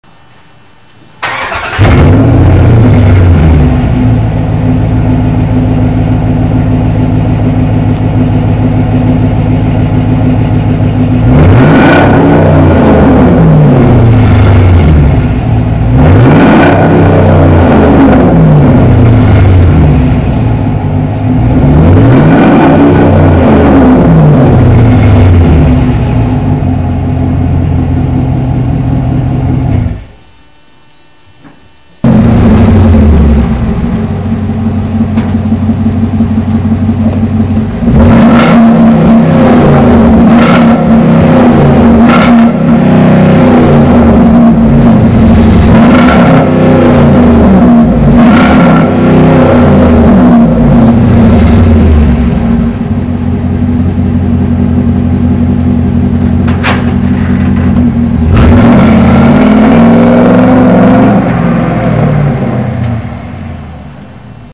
custom exhaust
The results? Sound is great outside, quieter inside though still some droning on the highway (see my post about sound-proofing with fiberglass insulation), but if I had to guess, I'd say there's not much more power or gas savings compared to stock.
truck_rev.mp3